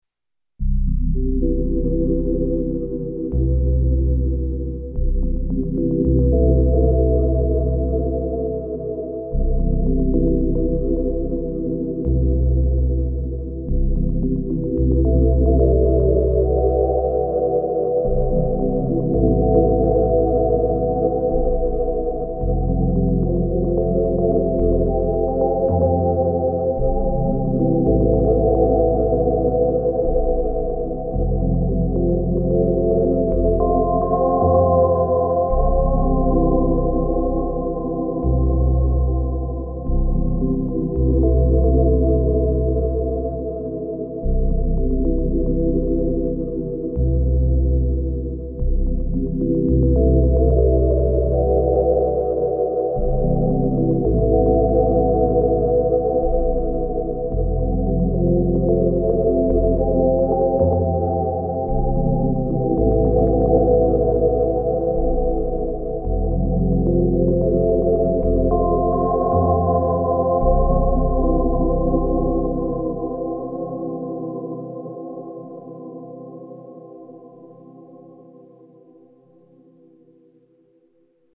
Dampened, foggy chords.
1 track for bass, the rest for the chords.
• LFO to panning for each note (except the bass)
• Chords go through the FX track set to modulated double notch.
• Slight delay time modulation.